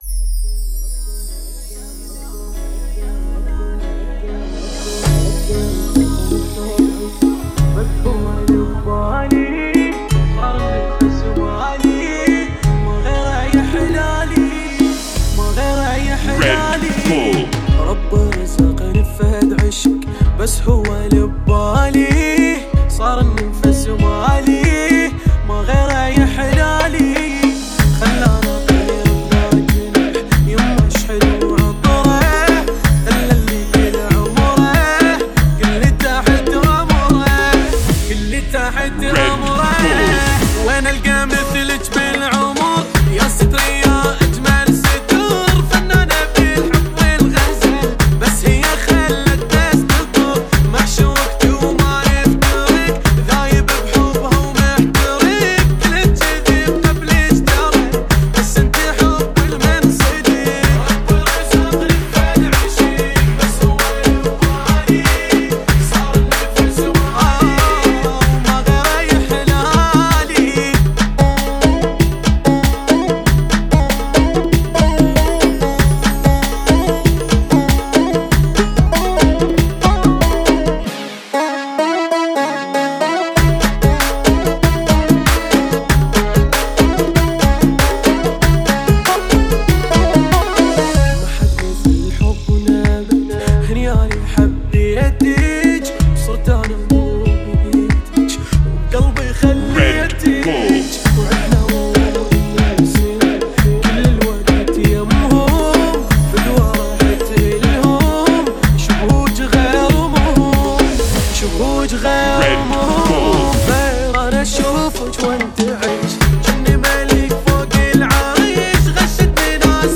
[ 95 bpm ]